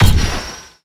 sw02_blasters_z6rotaryblaster_laser_close_var_01.wav